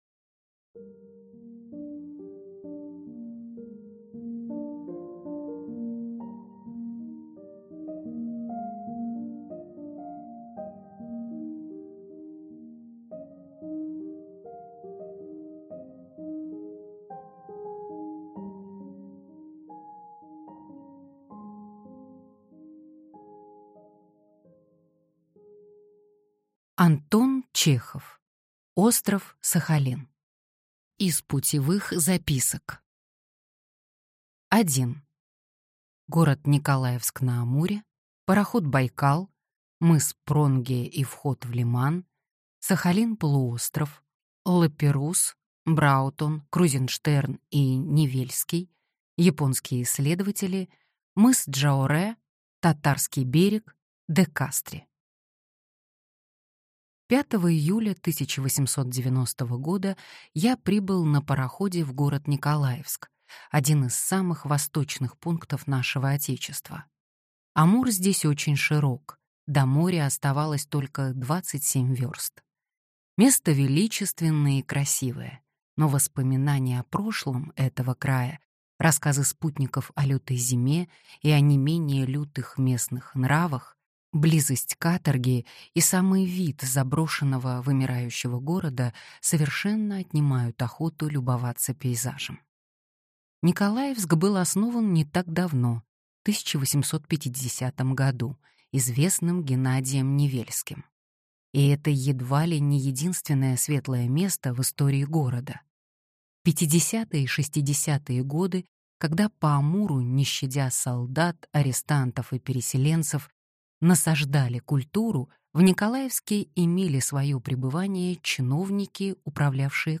Аудиокнига Остров Сахалин | Библиотека аудиокниг
Прослушать и бесплатно скачать фрагмент аудиокниги